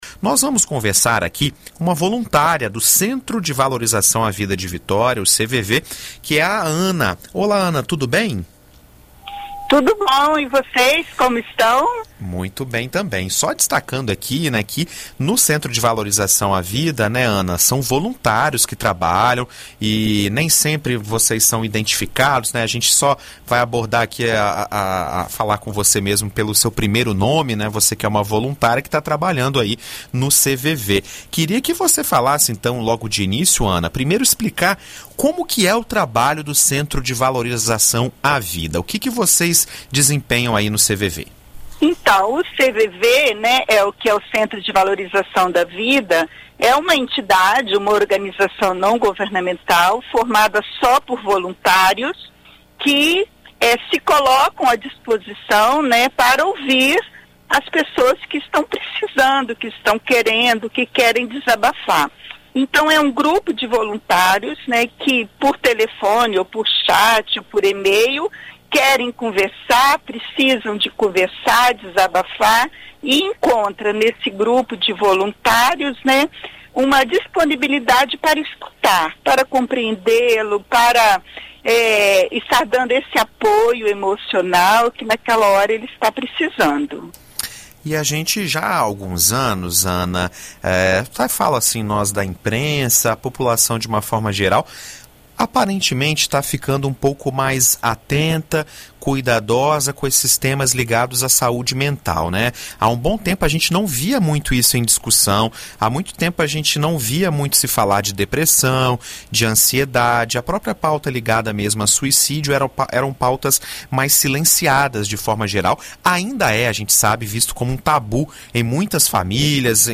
Em entrevista